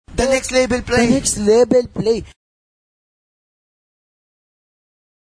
забавный голос
голосовые